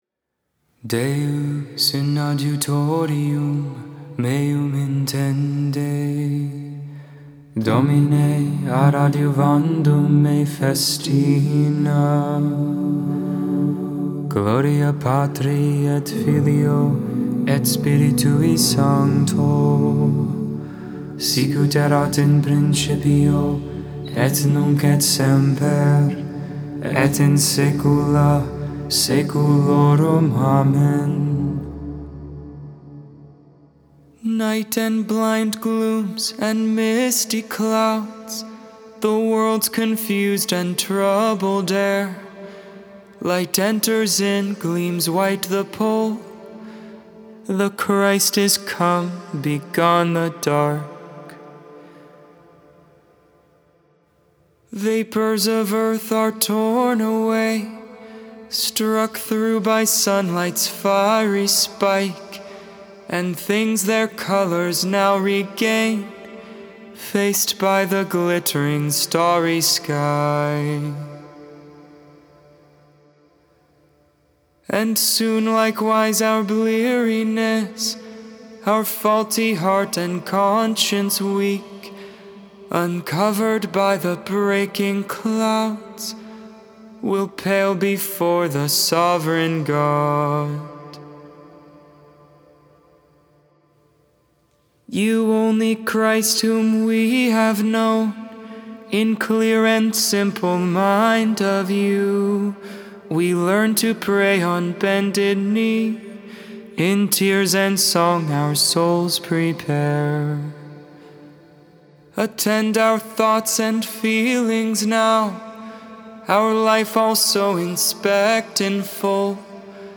Gregorian